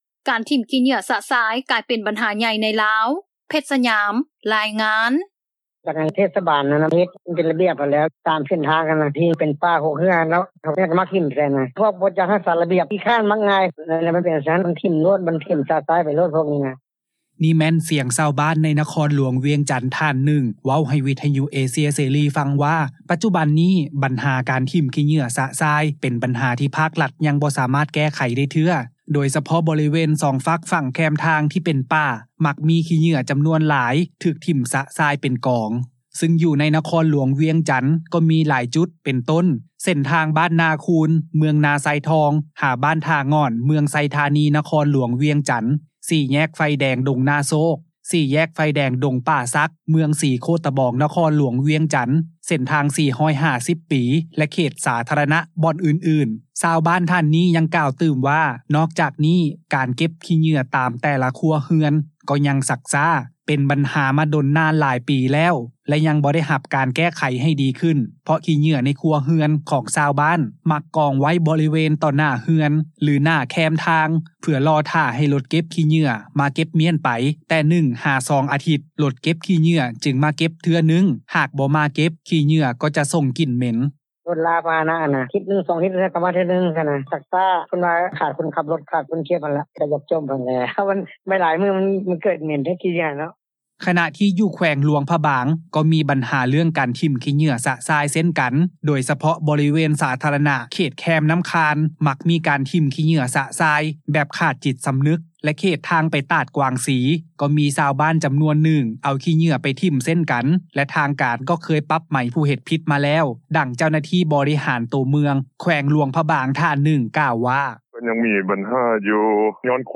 ນີ້ແມ່ນສຽງຊາວບ້ານ ໃນນະຄອນຫຼວງວຽງຈັນ ທ່ານໜຶ່ງ ເວົ້າໃຫ້ວິທຍຸເອເຊັຽເສຣີ ຟັງ ວ່າ ປັດຈຸບັນນີ້ ບັນຫາການຖິ້ມຂີ້ເຫຍື້ອ ຊະຊາຍ ເປັນບັນຫາ ທີ່ພາກລັດ ຍັງບໍ່ສາມາດແກ້ໄຂ ໄດ້ເທື່ອ ໂດຍສະເພາະ ບໍລິເວນສອງຝັ່ງແຄມທາງ ທີ່ເປັນປ່າ ມັກມີຂີ້ເຫຍື້ອຈໍານວນຫຼາຍ ຖືກຖິ້ມຊະຊາຍ ເປັນກອງ.